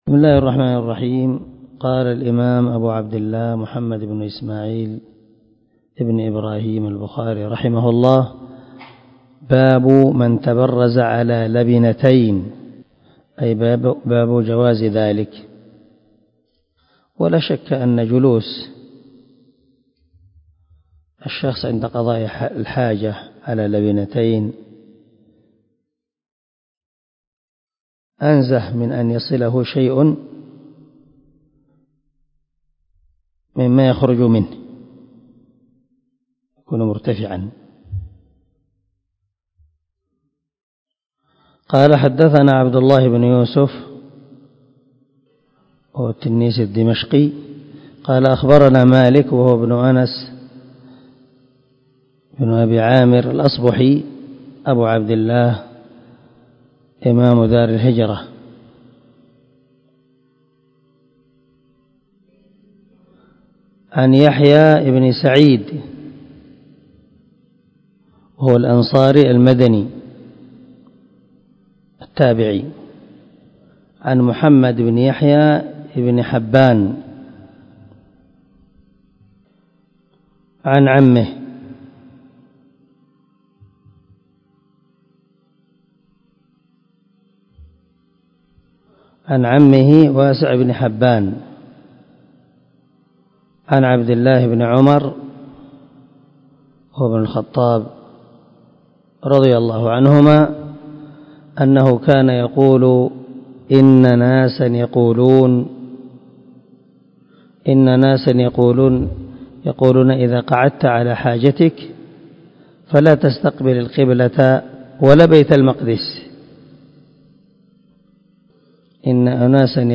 136الدرس 12 من شرح كتاب الوضوء حديث رقم ( 145 ) من صحيح البخاري